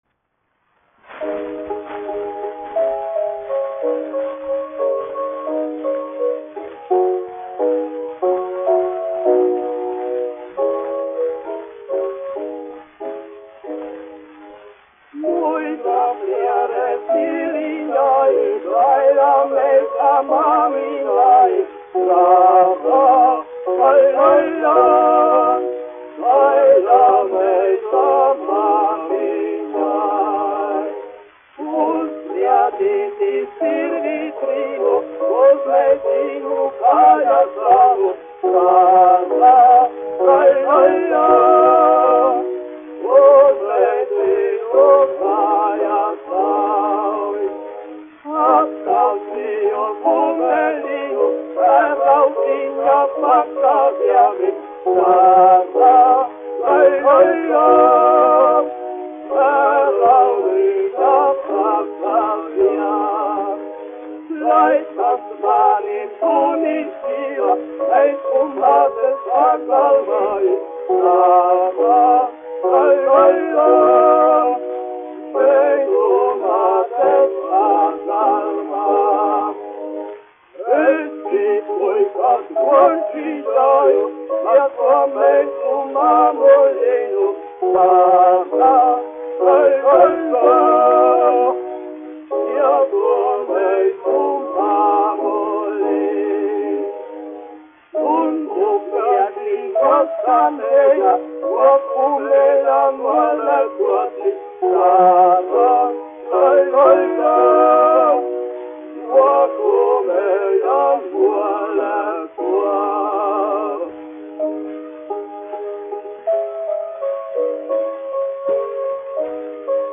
1 skpl. : analogs, 78 apgr/min, mono ; 25 cm
Latviešu tautasdziesmas
Vokālie dueti ar klavierēm
Rīgas Jaunā Latviešu teātra dziedātāji
Skaņuplate